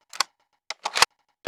oicw_reload.wav